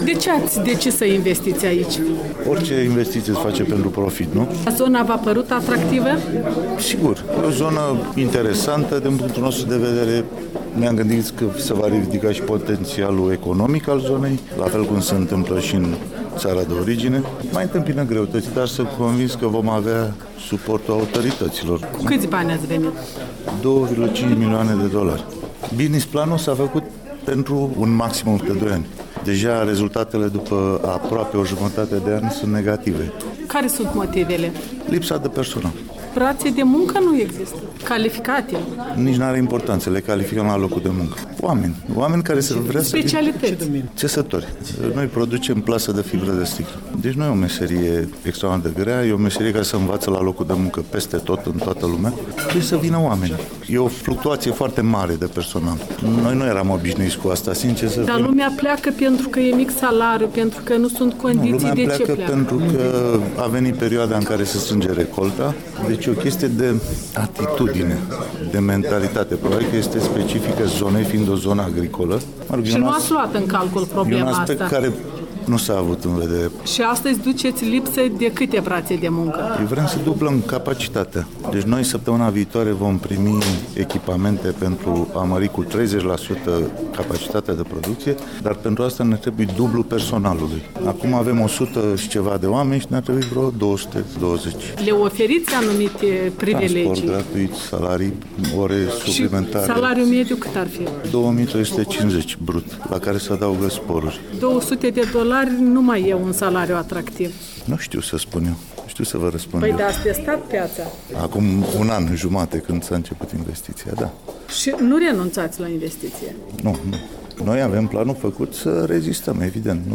Interviurile Europei Libere